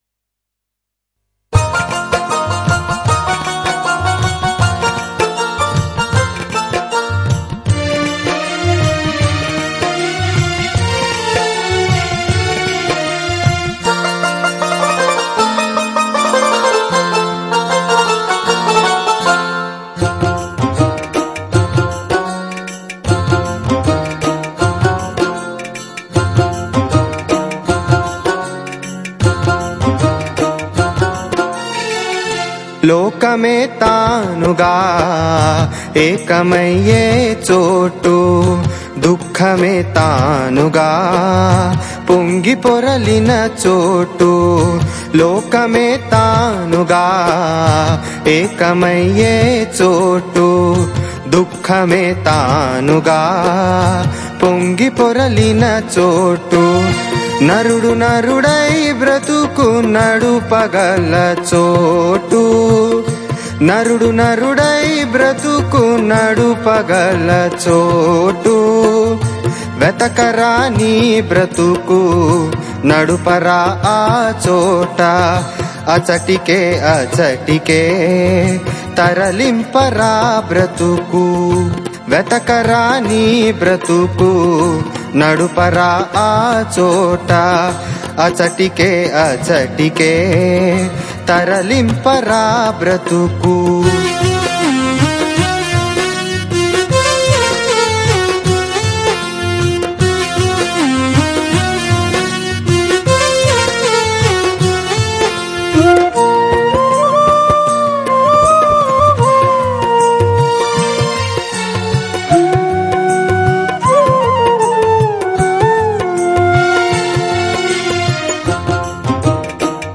Ragam - Chakravakam